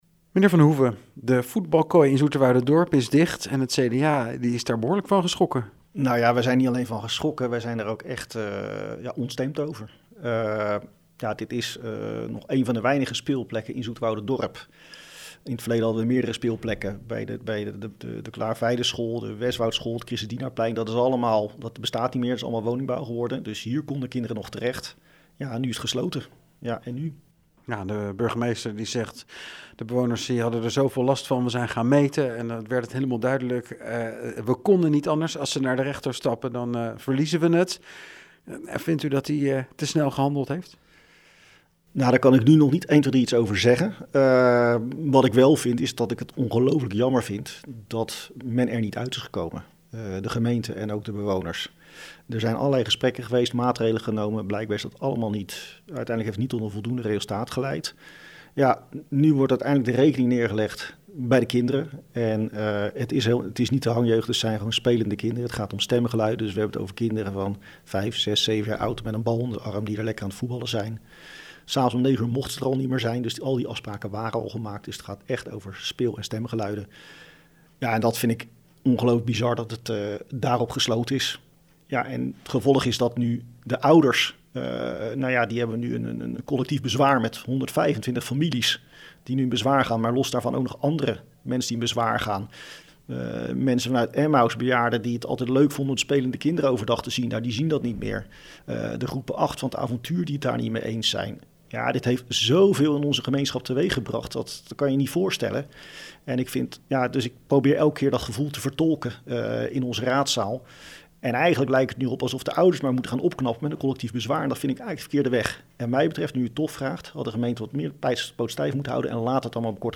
CDA Zoeterwoude fractievoorzitter Matthijs van der Hoeven over de sluiting van de voetbalkooi: